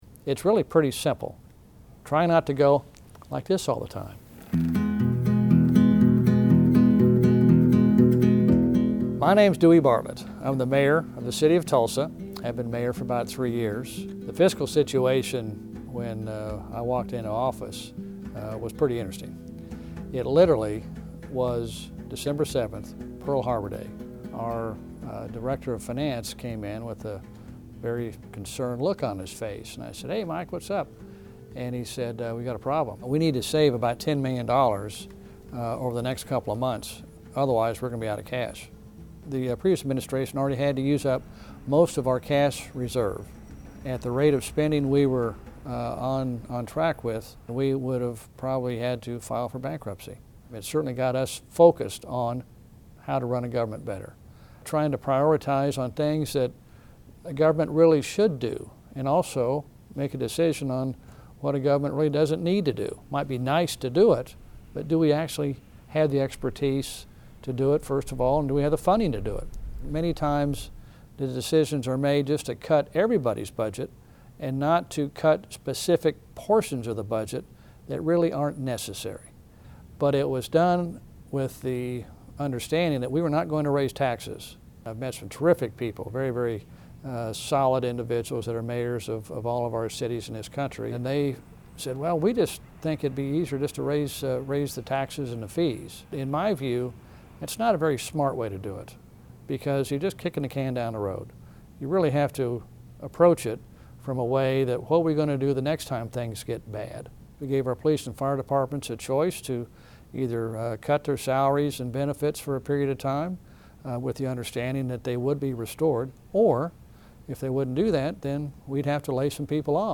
Reason TV talked with Bartlett in his office to talk about how to balance a city's budget without raising taxes.